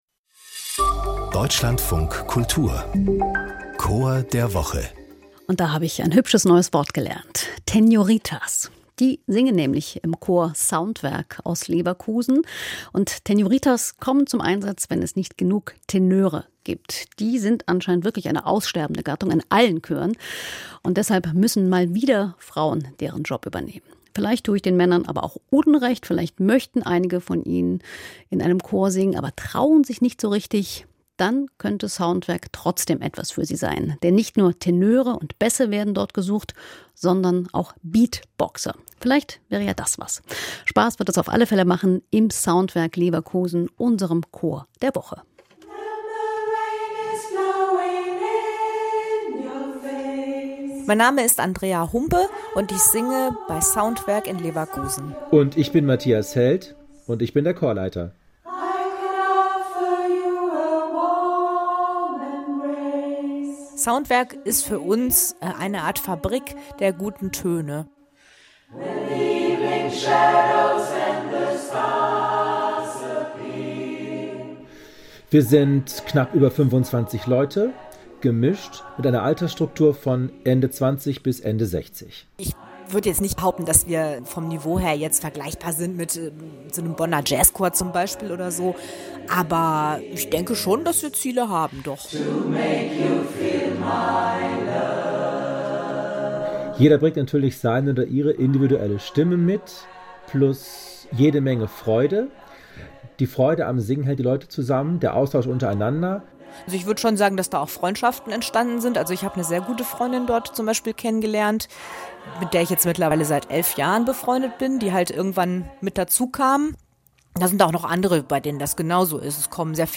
Chor der Woche - Soundwerk Leverkusen